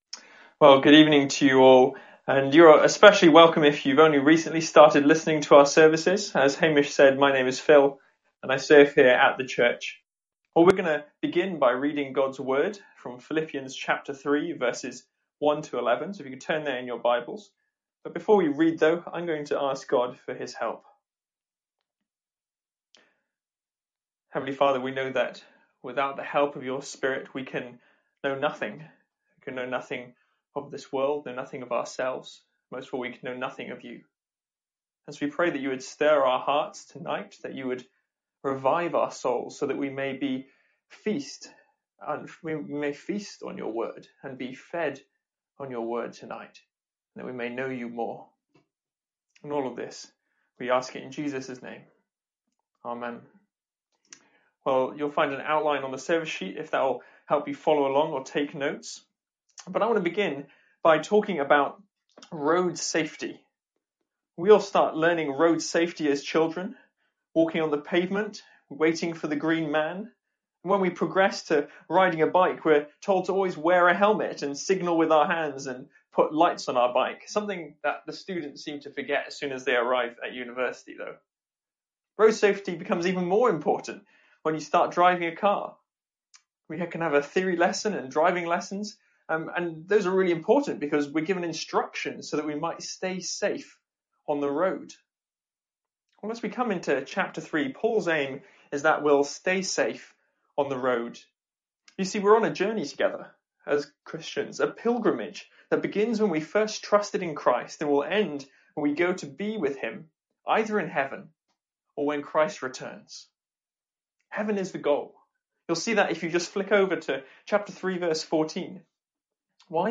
Sermons | St Andrews Free Church
From our evening services in Philippians.